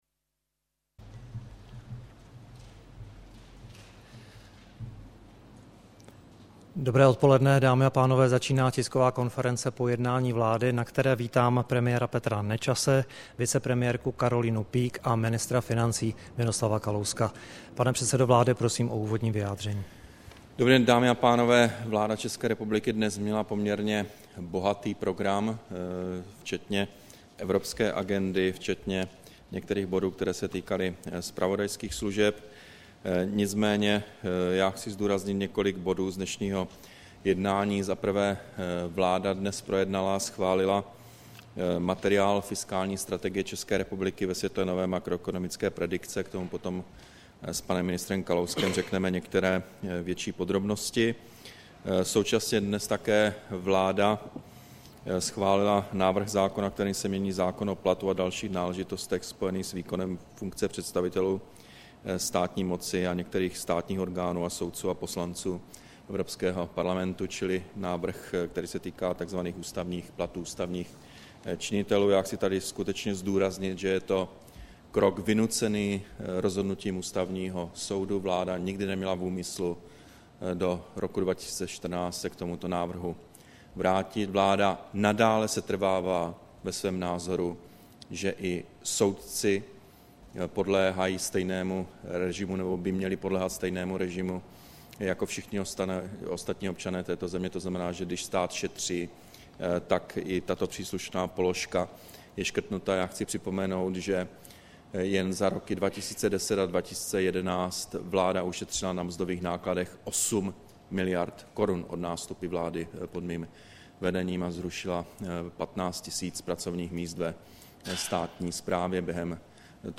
Tisková konference po jednání vlády, 19. července 2012